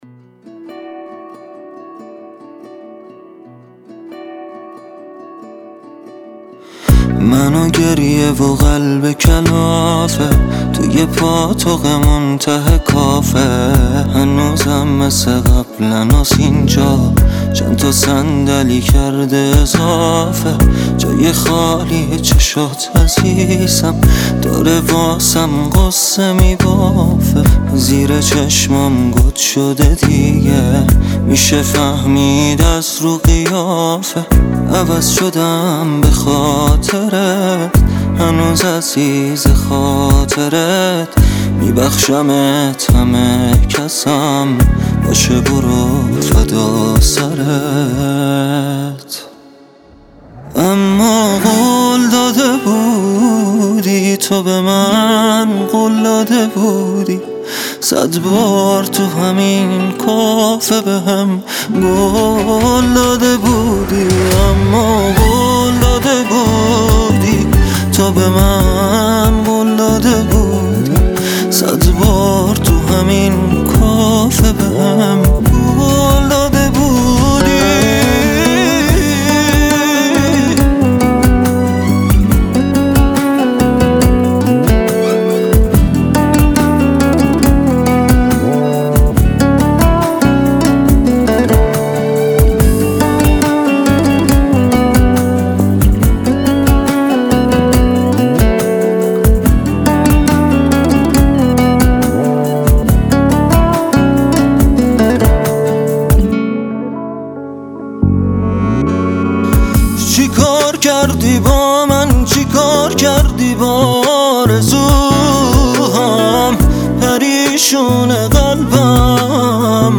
موزیک چس ناله ای